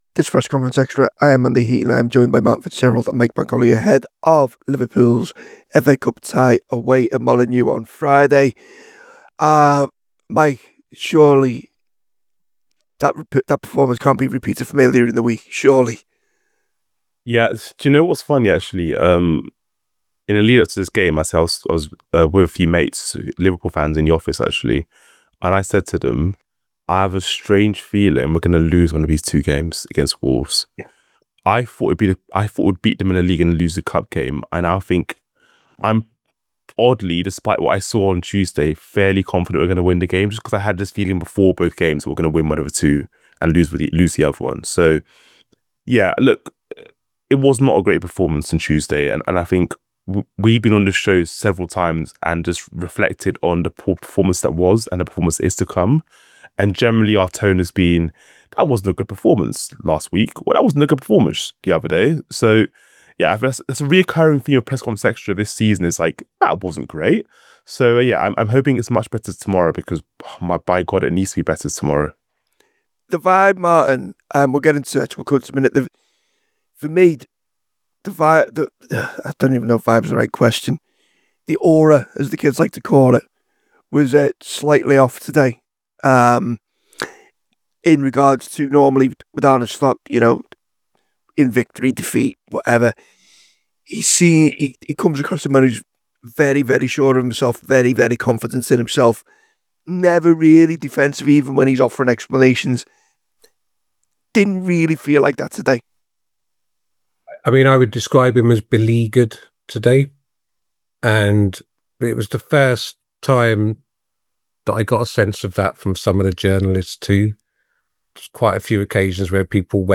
Below is a clip from the show – subscribe for more on the Liverpool v Wolves press conference…